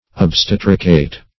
Search Result for " obstetricate" : The Collaborative International Dictionary of English v.0.48: Obstetricate \Ob*stet"ri*cate\ ([o^]b*st[e^]t"r[i^]*k[=a]t), v. i. [L. obstetricatus, p. p. of obstetricare, fr. obstetrix.] To perform the office of midwife.
obstetricate.mp3